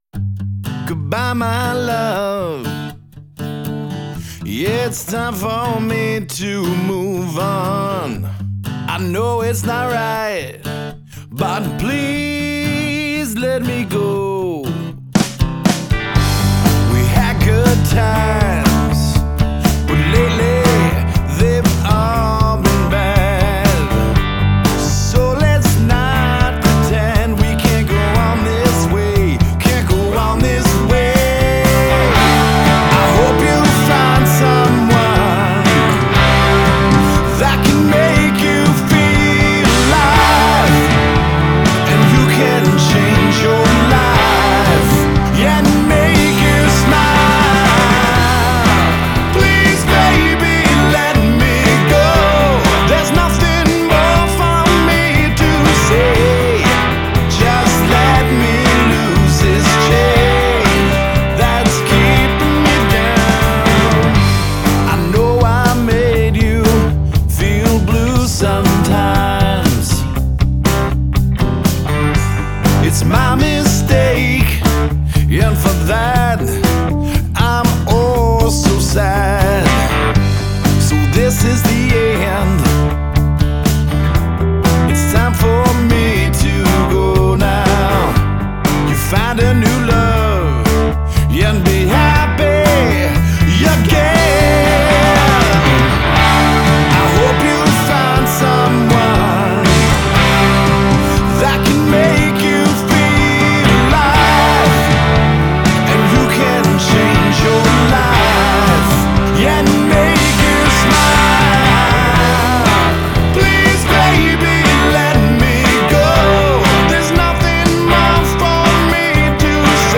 Having the studio musicians come in and take my ideas and form a finished song was unbelievable.